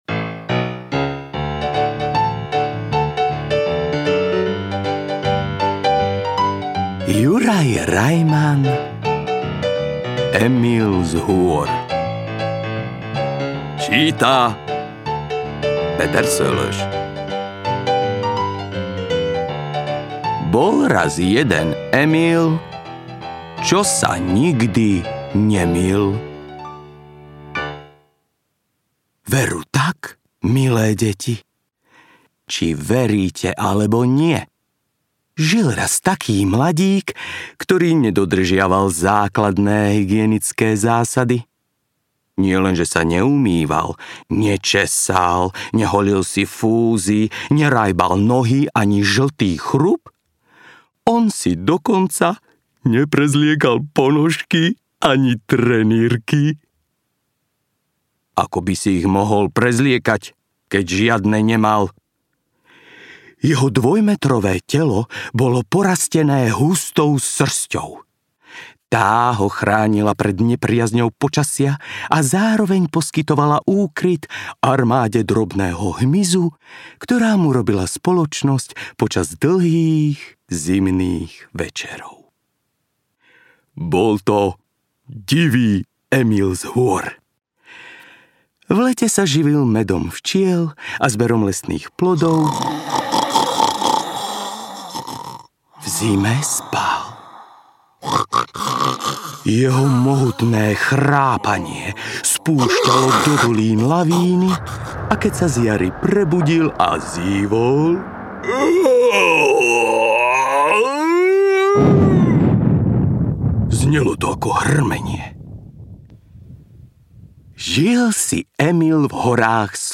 Emil z hôr audiokniha
Ukázka z knihy